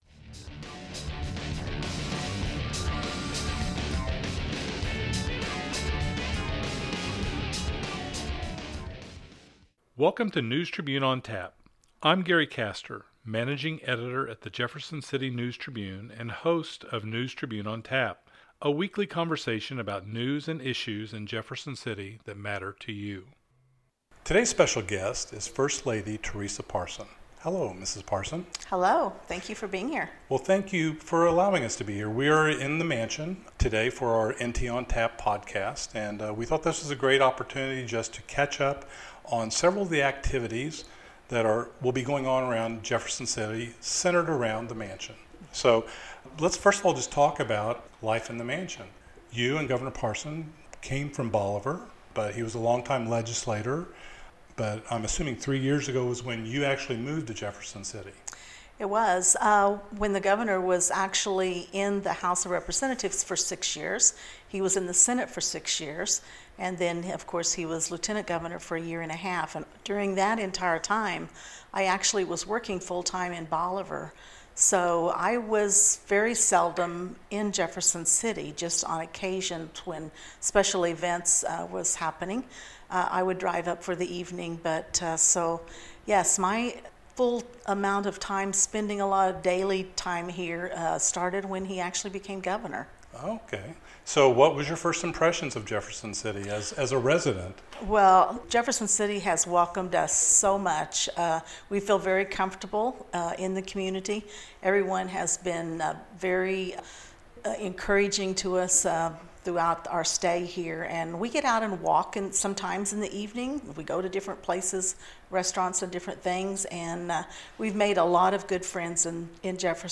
Missouri First Lady Teresa Parson chats about fall festival, new cookbook